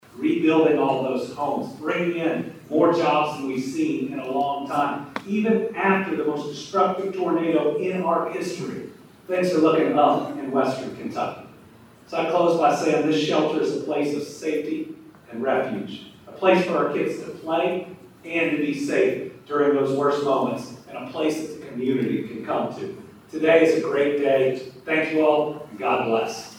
A moment of pride and resilience was on full display in Hopkins County as Governor Andy Beshear, school officials, and community members gathered to celebrate the opening of new auxiliary gyms and storm shelters at both local high schools Tuesday afternoon.